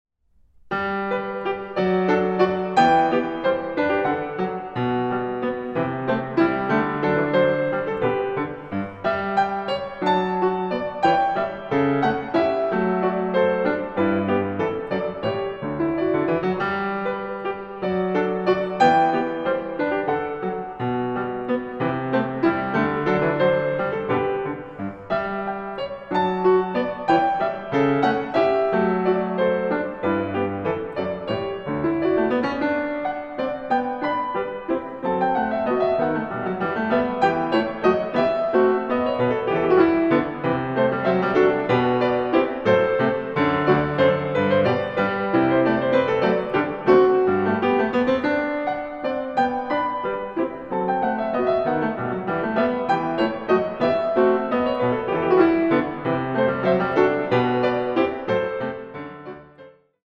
Piano
Recording: Jesus-Christus-Kirche Berlin-Dahlem, 2024